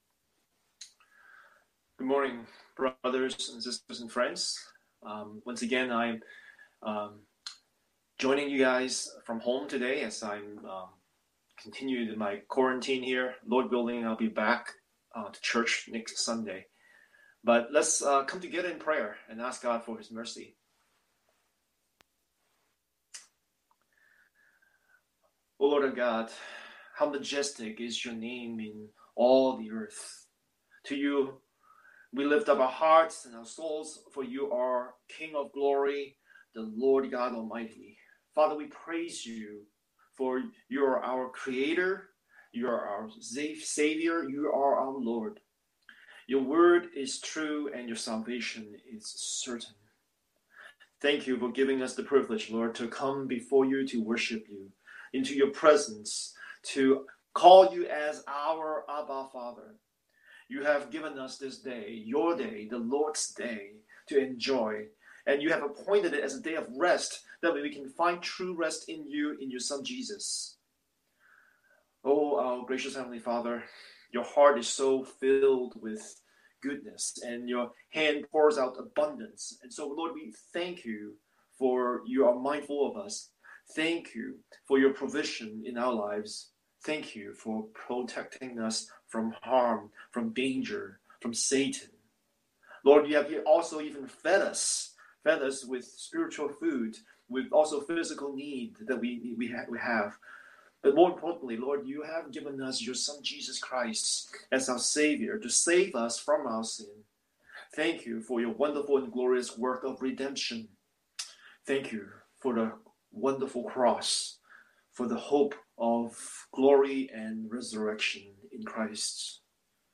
Scripture: Joshua 7:1-26 Series: Sunday Sermon